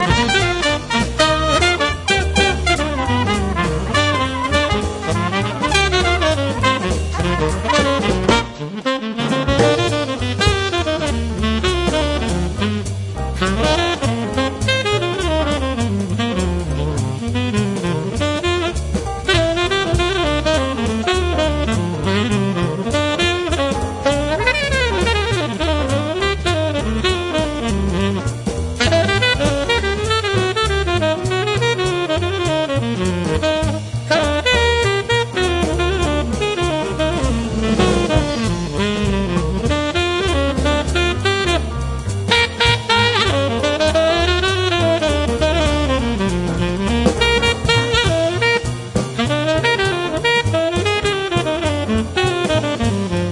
The Best In British Jazz
Recorded at the Red Gables Studio, September 26th 2006